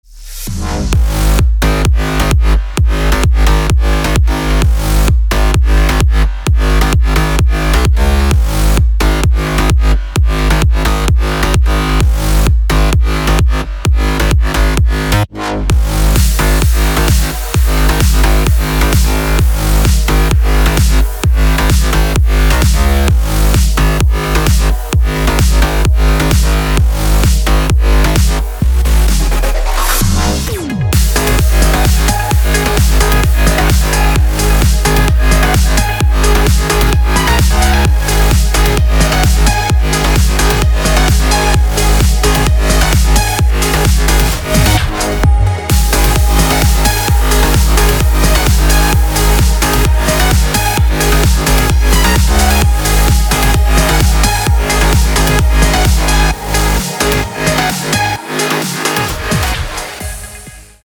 • Качество: 256, Stereo
dance
Electronic
электронная музыка
без слов
progressive trance
Trance